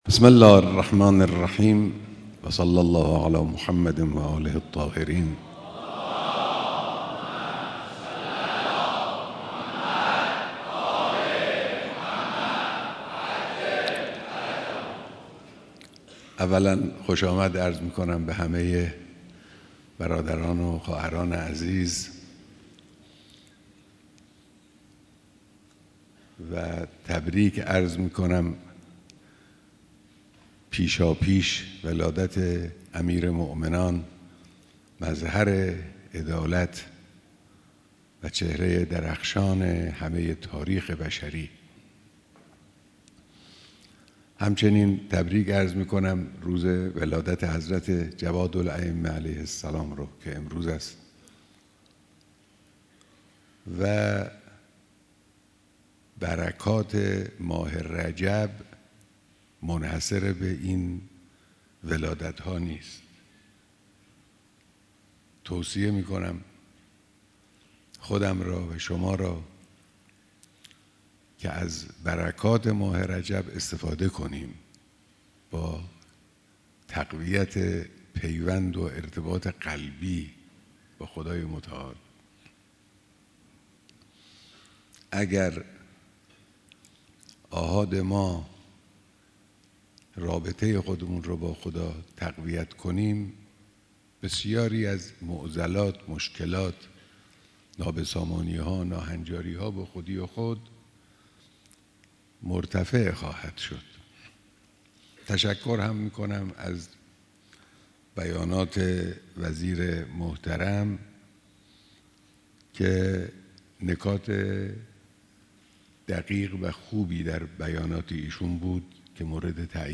بیانات در دیدار هزاران نفر از کارگران سراسر کشور